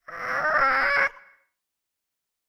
Minecraft Version Minecraft Version snapshot Latest Release | Latest Snapshot snapshot / assets / minecraft / sounds / mob / ghastling / ghastling3.ogg Compare With Compare With Latest Release | Latest Snapshot
ghastling3.ogg